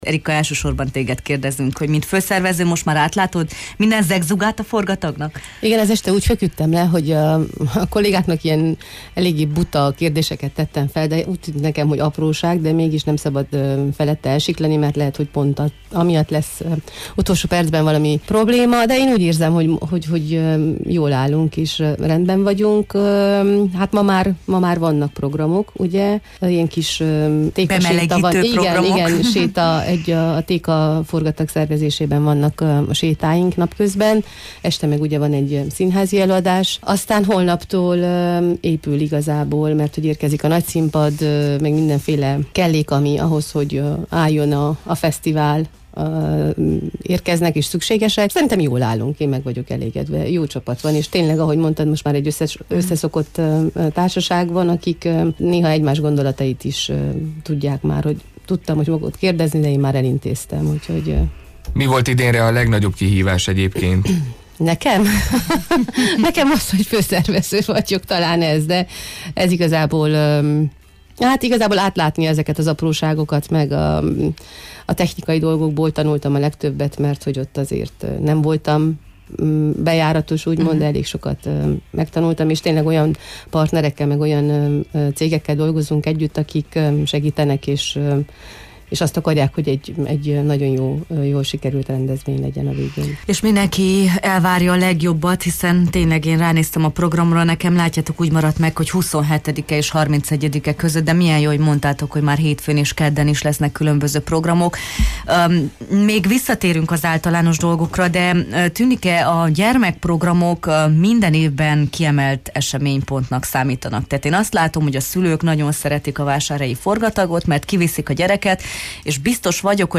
beszélgettünk a Jó reggelt, Erdély!-ben: